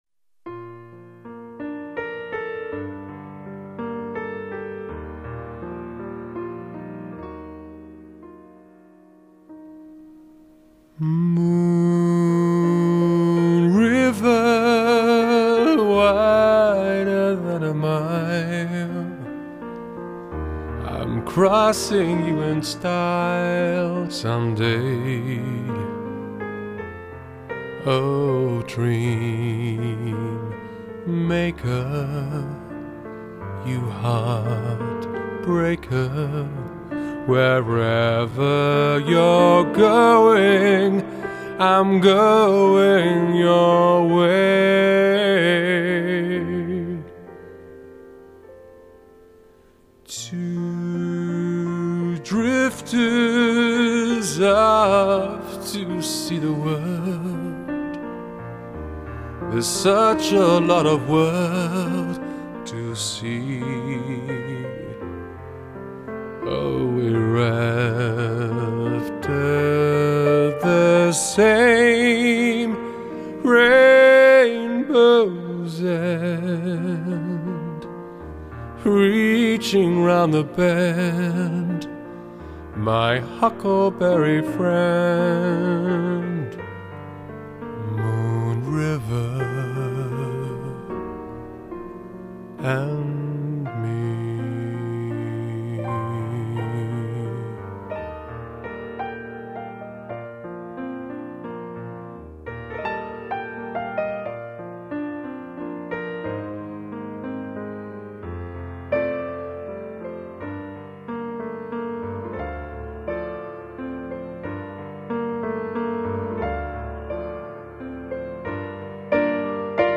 classic love songs